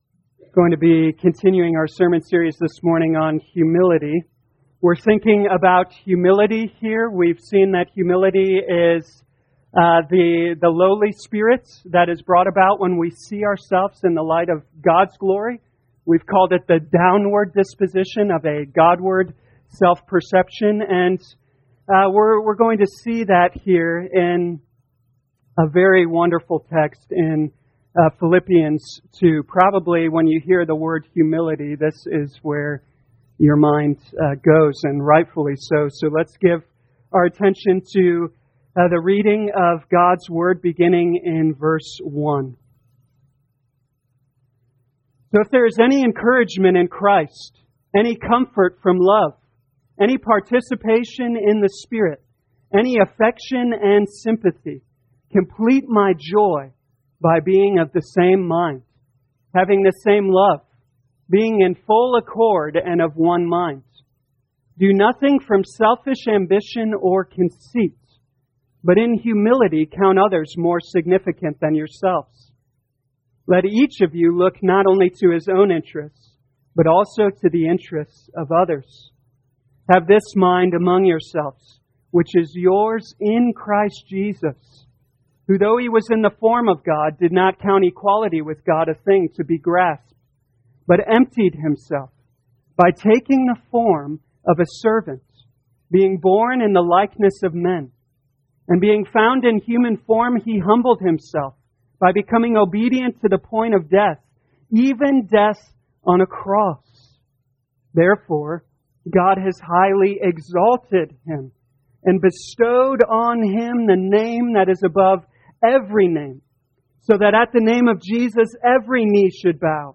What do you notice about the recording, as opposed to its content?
2021 Philippians Humility Morning Service Download